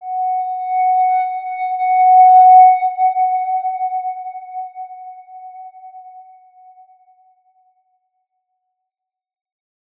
X_Windwistle-F#4-ff.wav